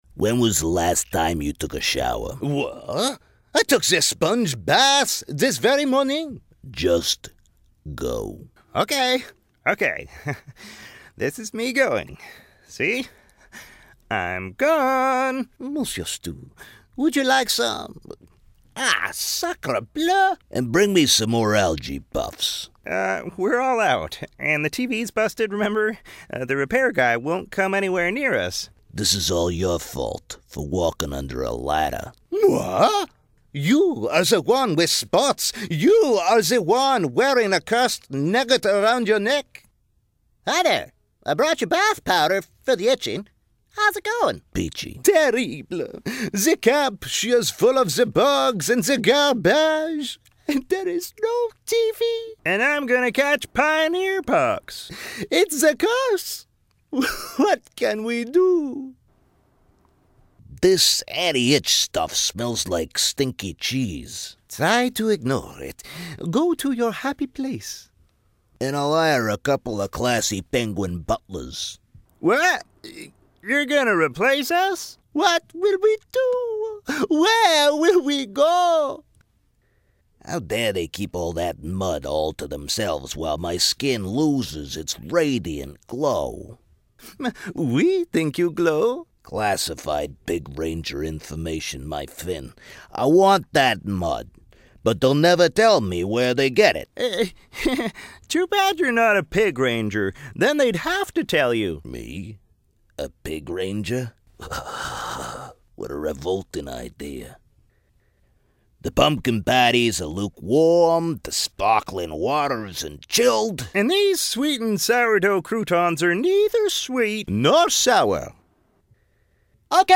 Voice Demos